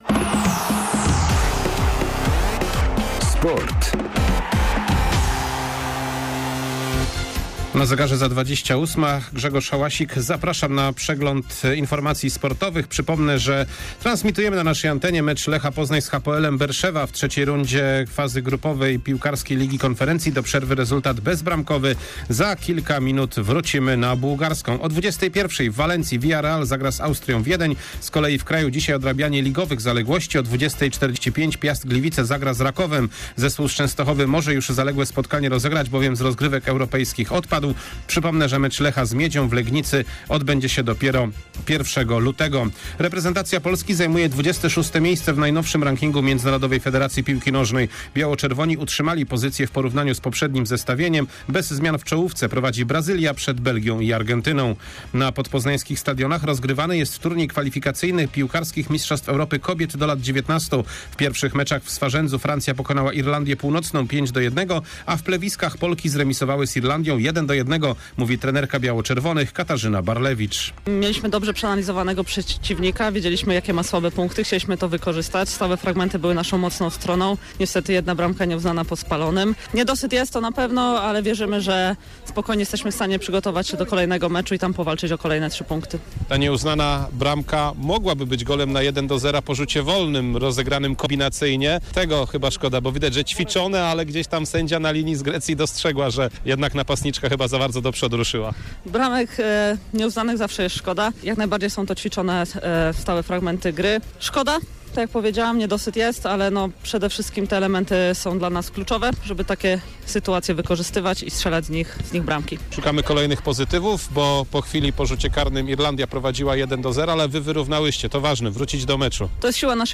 06.10.2022 SERWIS SPORTOWY GODZ. 19:05